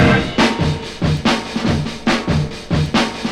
• 144 Bpm Modern Drum Loop Sample C Key.wav
Free drum groove - kick tuned to the C note. Loudest frequency: 1182Hz
144-bpm-modern-drum-loop-sample-c-key-Pz5.wav